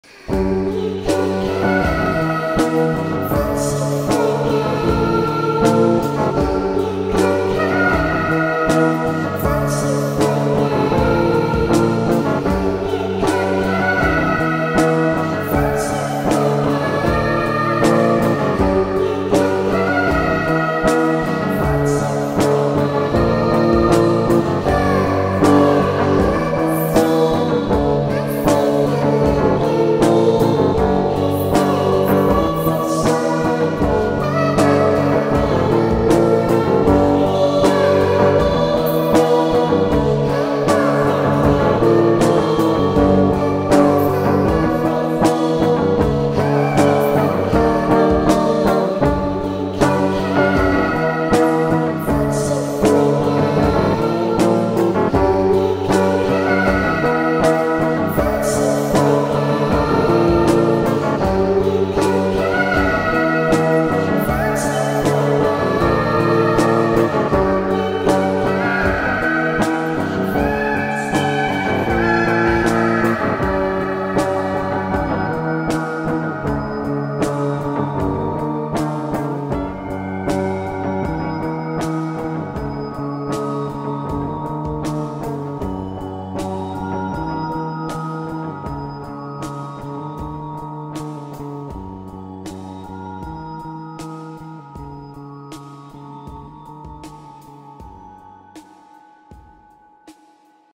با ریتمی سریع شده
عاشقانه خارجی